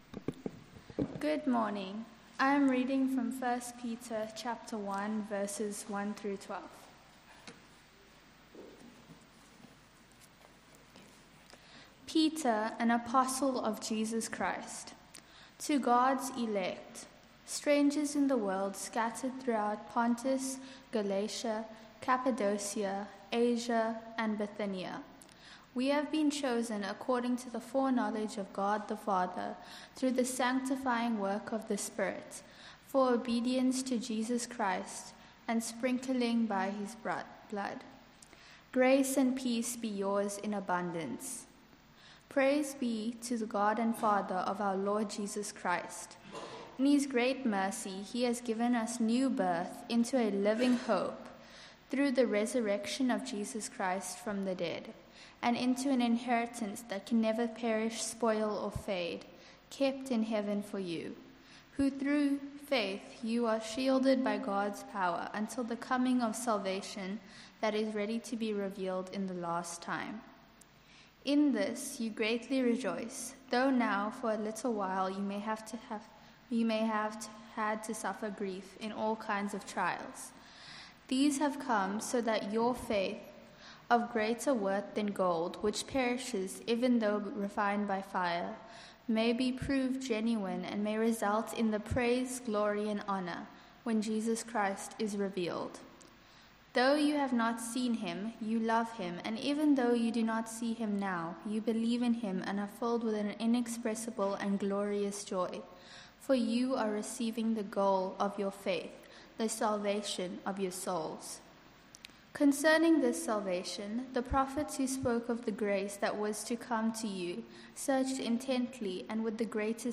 January 25, 2026 Harmony of Joy & Sorrow Series: 1 Peter Passage: 1 Peter 1:3-12 Service Type: Morning Service Topics: joy & sorrow « Becoming a people of hope living in a turbulent world.